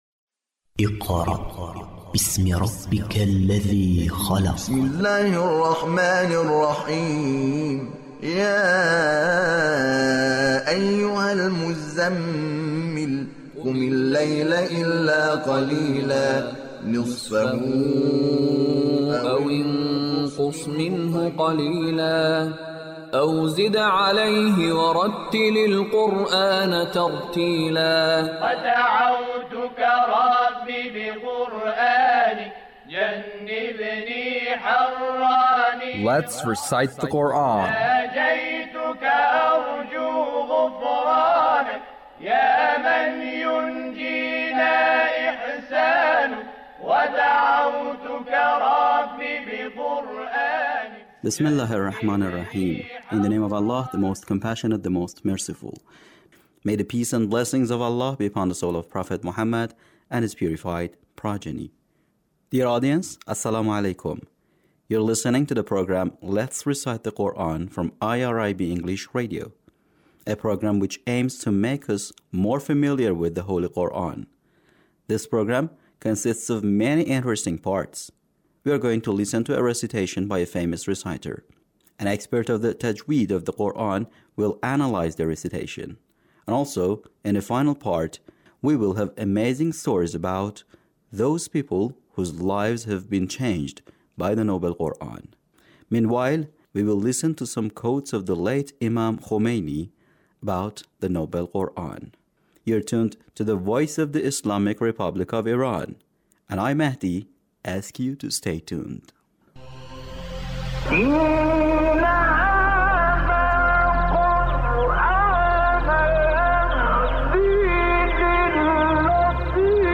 Recitation of surah al-Ra'd - Attractiveness of the Noble Quran